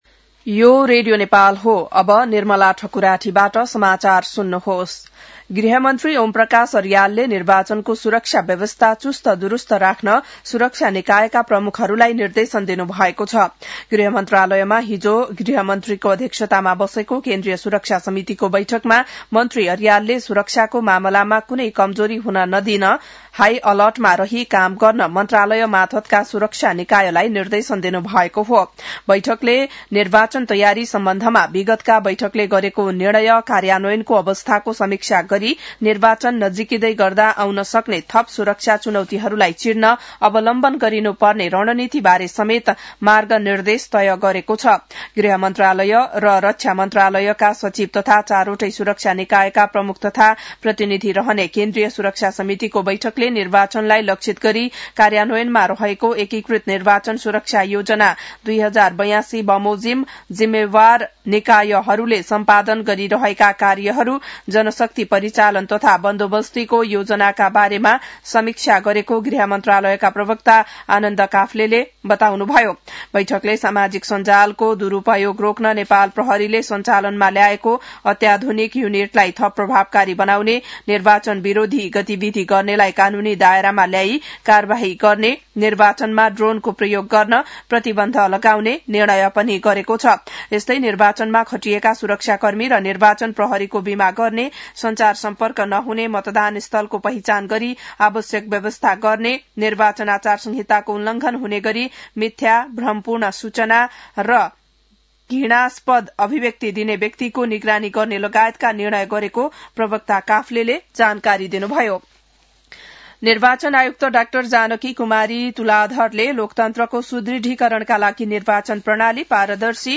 बिहान १० बजेको नेपाली समाचार : २५ माघ , २०८२